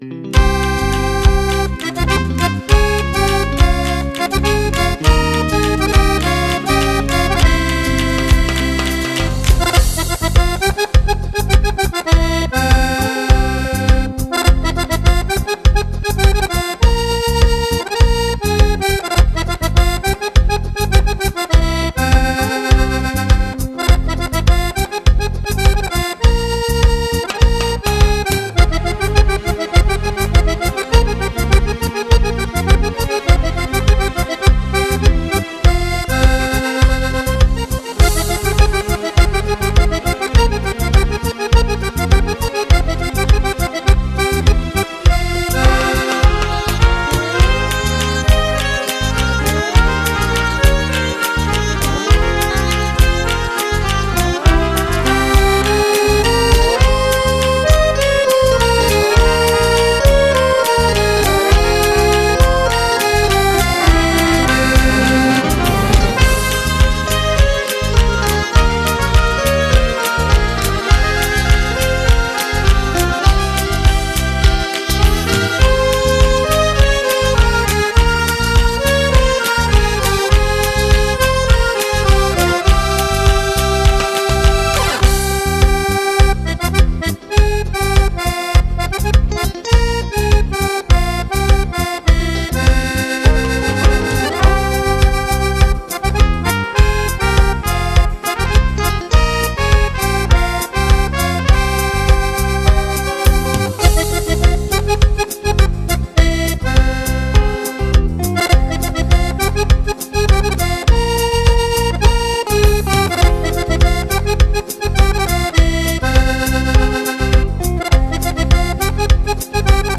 INTERVISTA andata in onda su RADIO SORRISO per promuovere il nostrro CD
intervista_radio_sorriso.mp3